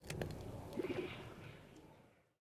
Minecraft Version Minecraft Version latest Latest Release | Latest Snapshot latest / assets / minecraft / sounds / block / trial_spawner / ambient2.ogg Compare With Compare With Latest Release | Latest Snapshot
ambient2.ogg